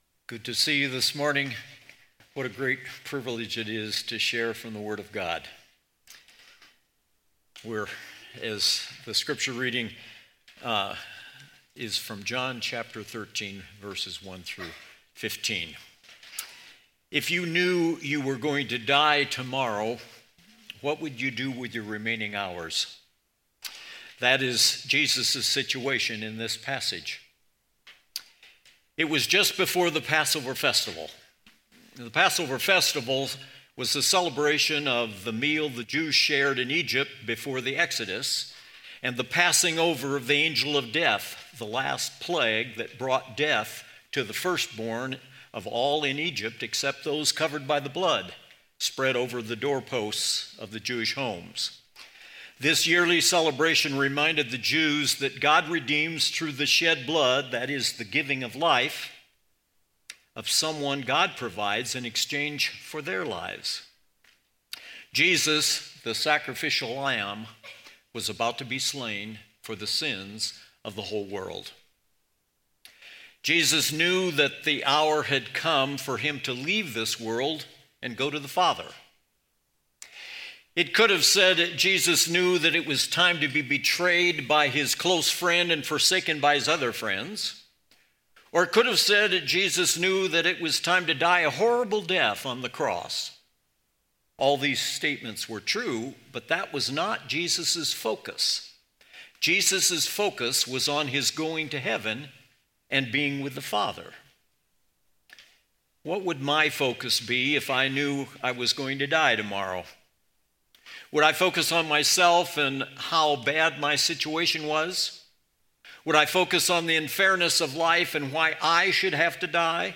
Weekly teaching audio brought to you by Redeemer Church in Bozeman, MT